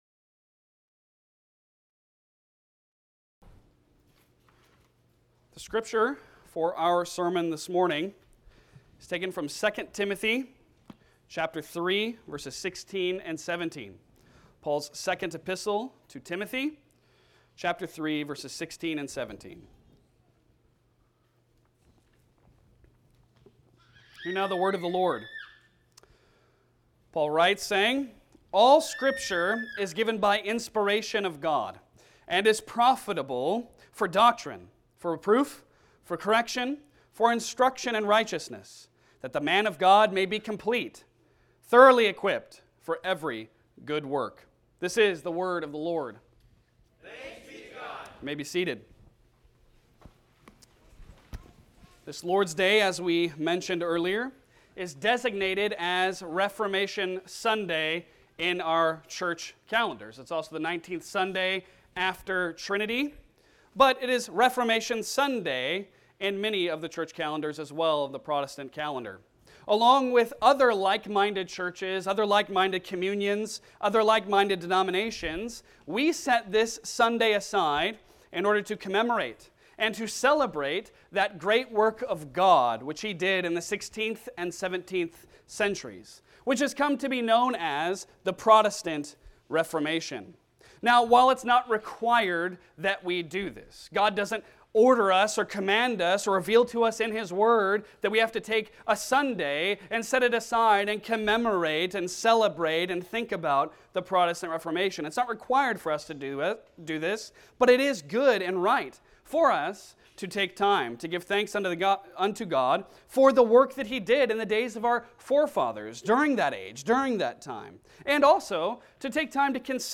2 Timothy 3:16-17 Service Type: Sunday Sermon Download Files Bulletin Topics